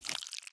Minecraft Version Minecraft Version latest Latest Release | Latest Snapshot latest / assets / minecraft / sounds / item / honeycomb / wax_on2.ogg Compare With Compare With Latest Release | Latest Snapshot